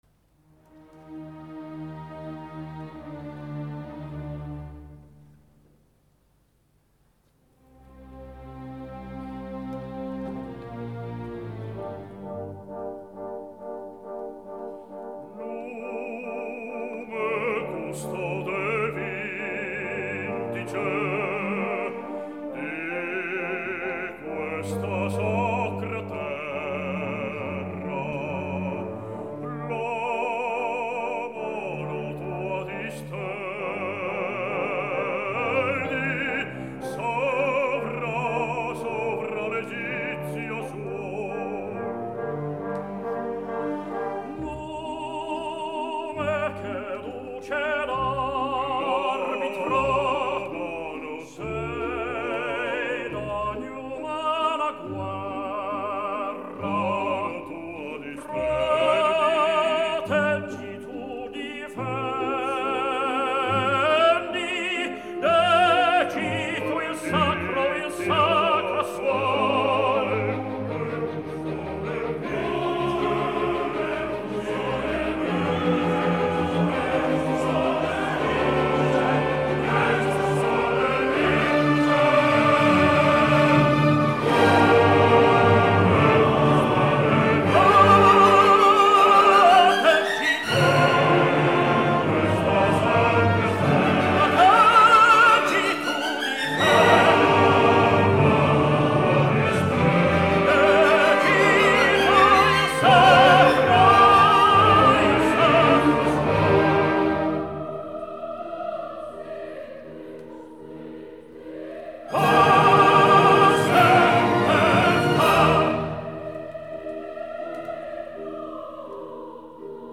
Запись 3-16 IX. 1955, театр "Ла Скала", Милан.